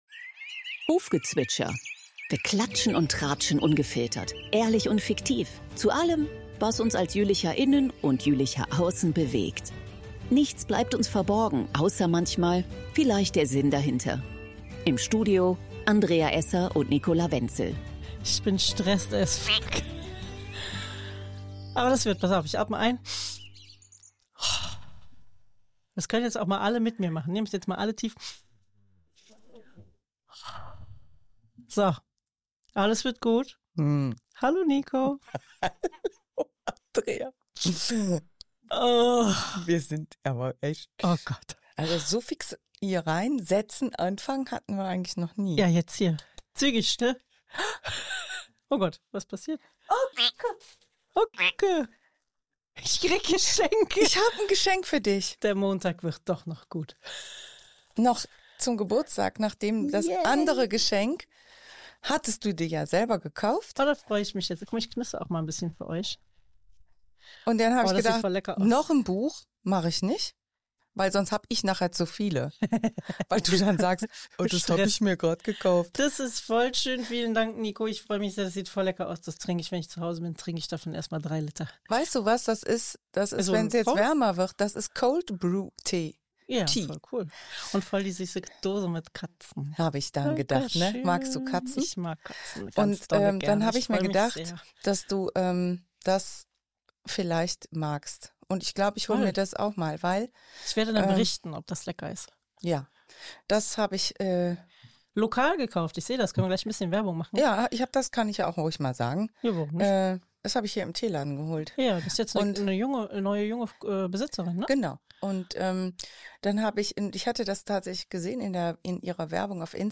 Als Zwischenspiele gibt es viel Gelächter.